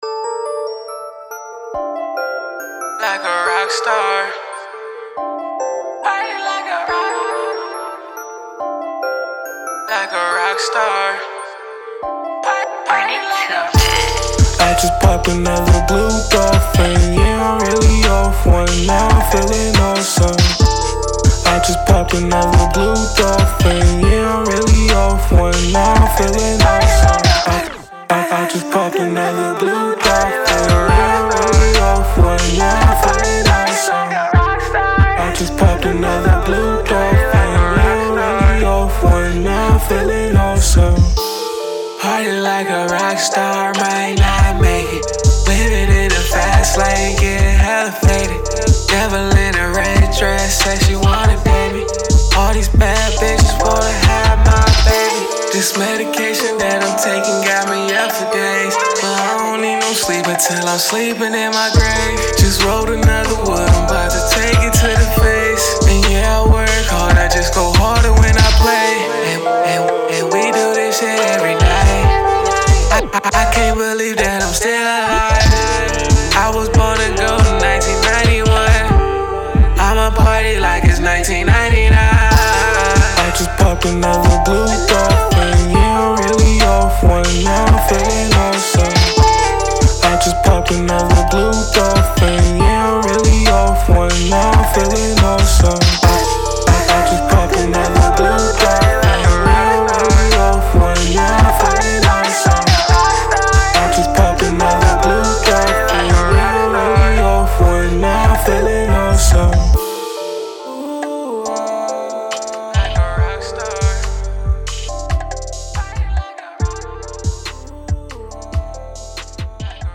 Rock
Description: A party record that'll make you wana move.